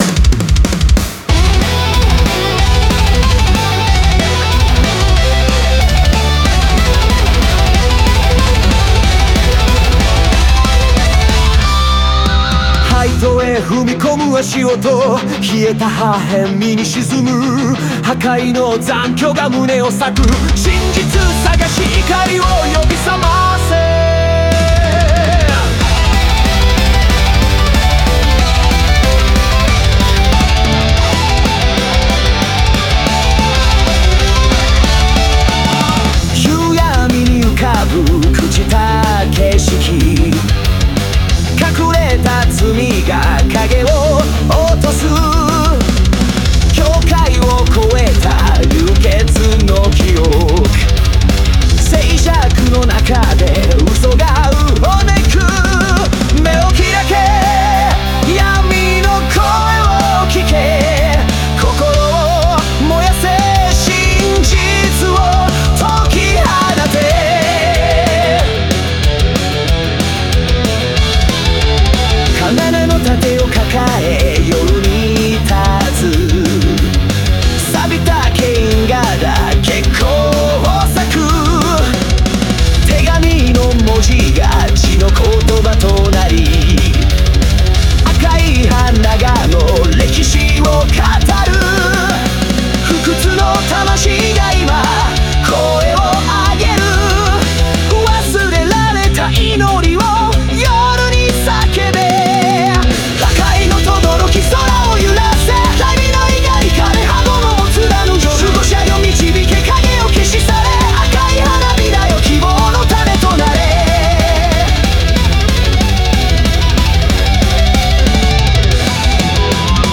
Melodic Power Metal